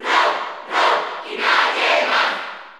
Category: Crowd cheers (SSBU) You cannot overwrite this file.
R.O.B._Cheer_Spanish_NTSC_SSB4_SSBU.ogg